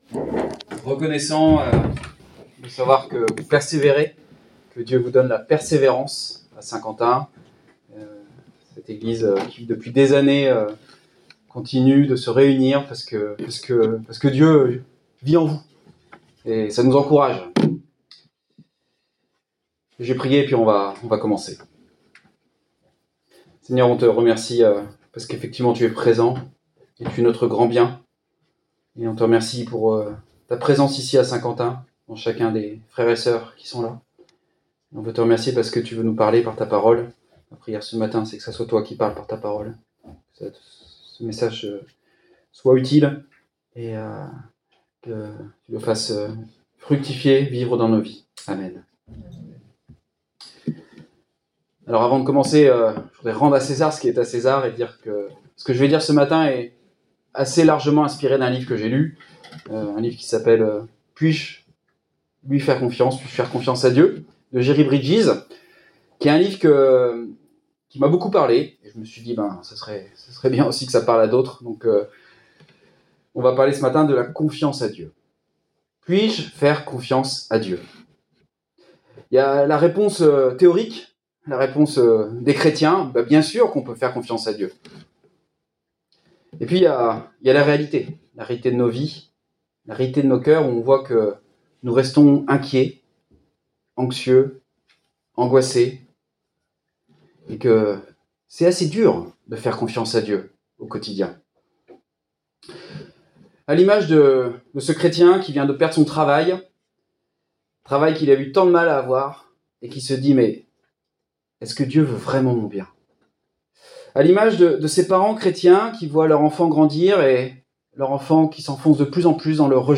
Le SAS de la confiance Prédicateur